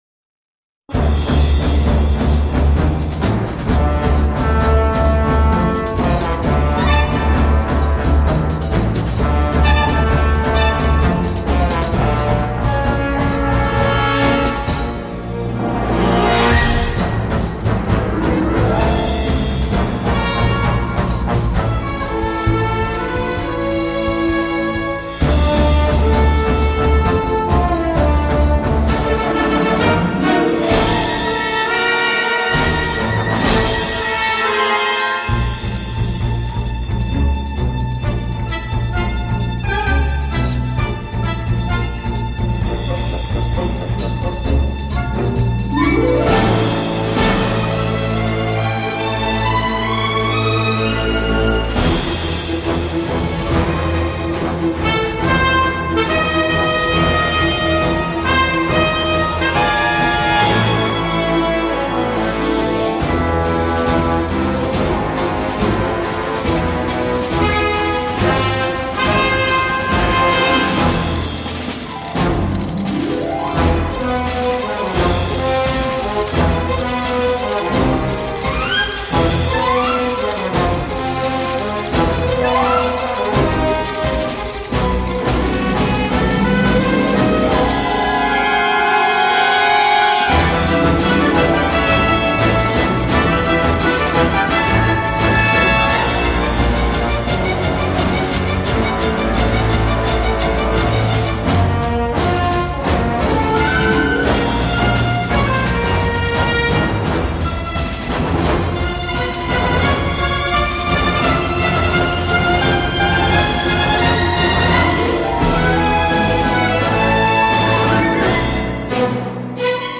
C’est enlevé, trépidant et très bien orchestré.